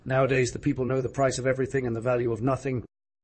tortoise-tts-v2 like 226